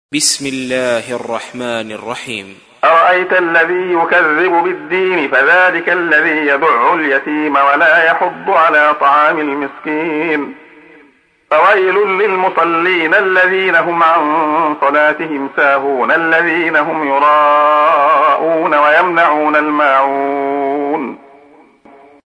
تحميل : 107. سورة الماعون / القارئ عبد الله خياط / القرآن الكريم / موقع يا حسين